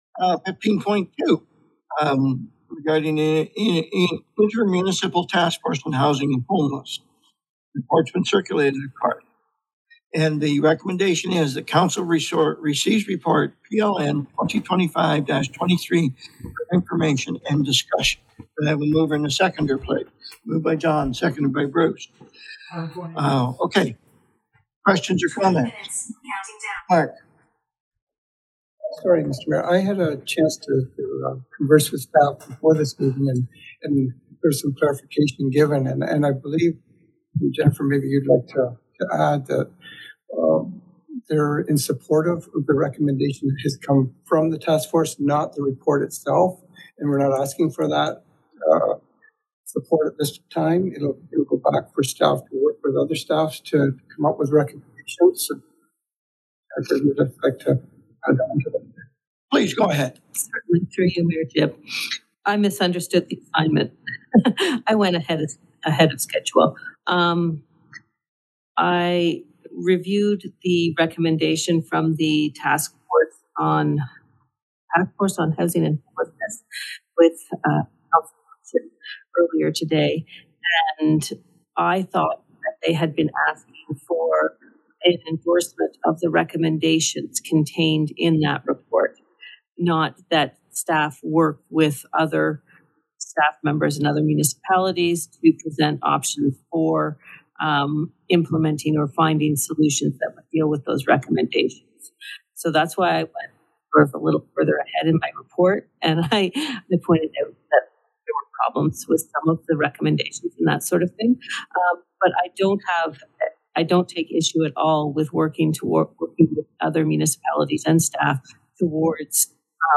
Hamilton Township received a report on affordable housing and homelessness at its July 15 meeting, with the goal of collaborating with others rather than going it alone.
Here is the report presentation and discussion: